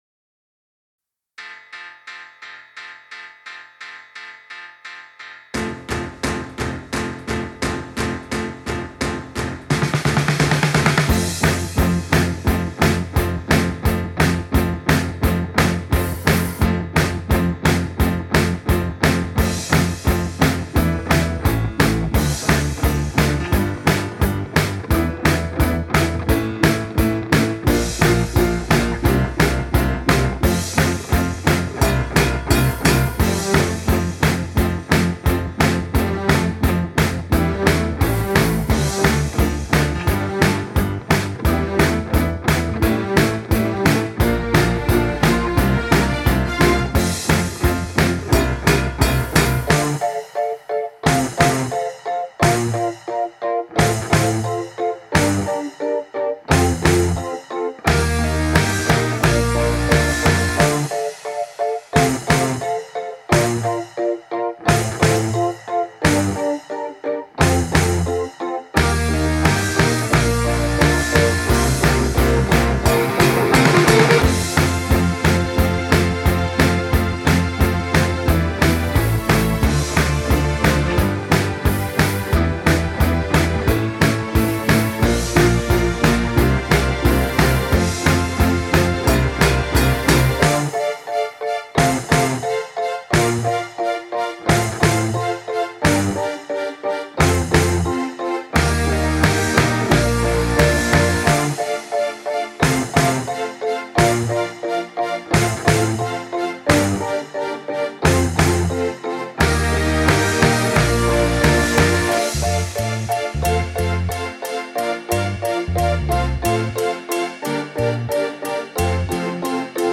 Mr Blue Sky – Backing | Ipswich Hospital Community Choir